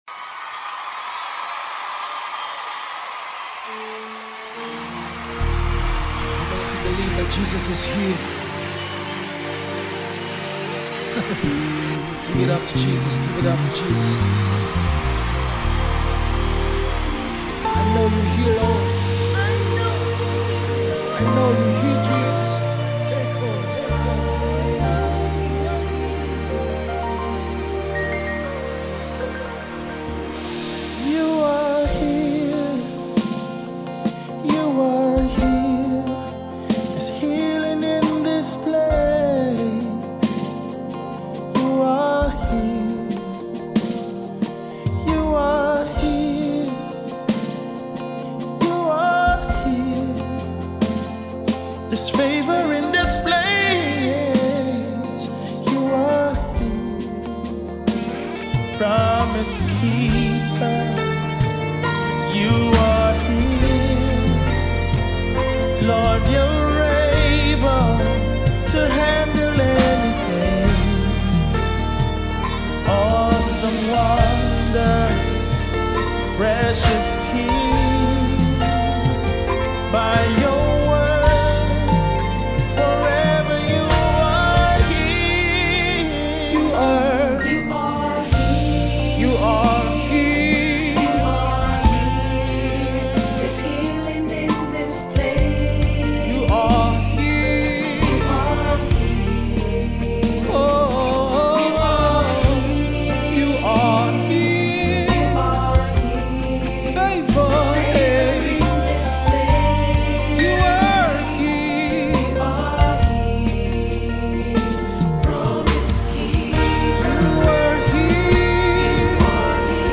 Nigerian-born multi-talented Gospel artist